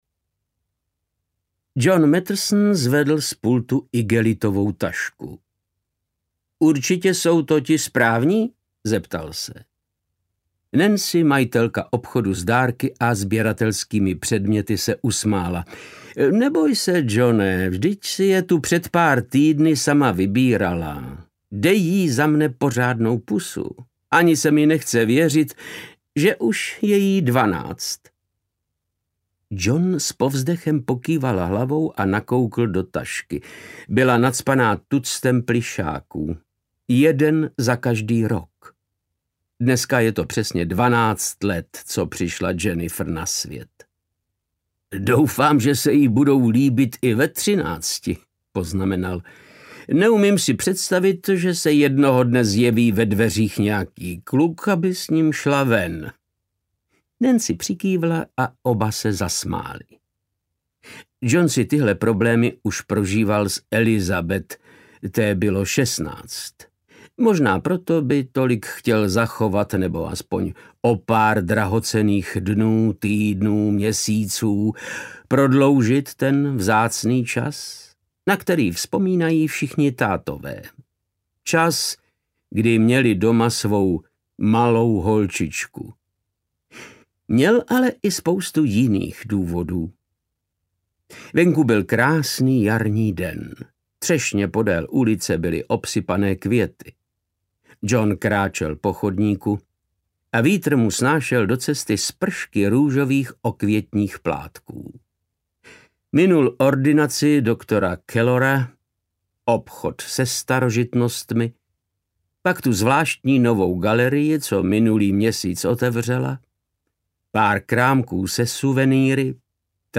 Vteřinu poté audiokniha
Ukázka z knihy
• InterpretVáclav Knop